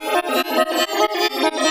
RI_ArpegiFex_140-03.wav